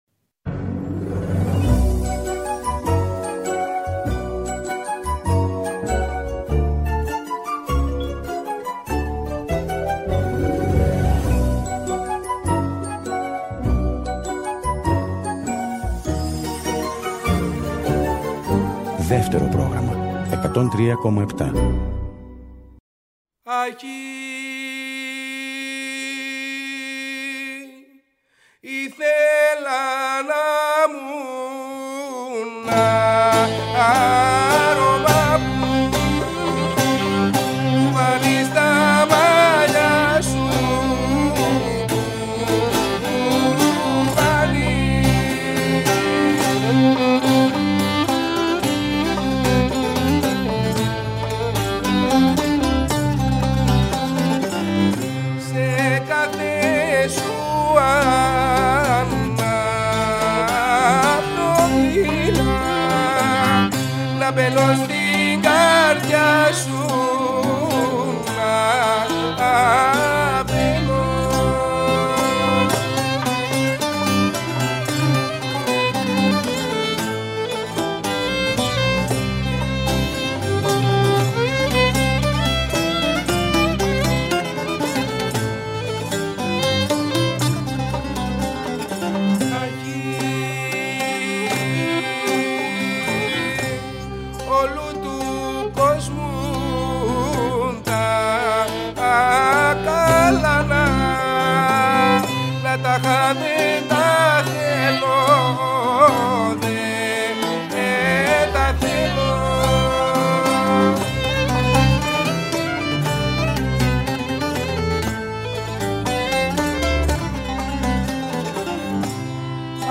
μια μουσική ομάδα
λαούτο τραγούδι
βιολί
κρουστά τραγούδι
κοντραμπάσο
Ένα πανόραμα της παραδοσιακής μουσικής της χώρας μας μέσα από ολόφρεσκες, ζωντανές ηχογραφήσεις με σύγχρονα συγκροτήματα
ηχογραφήθηκαν ζωντανά στο στούντιο Ε της Ελληνικής Ραδιοφωνίας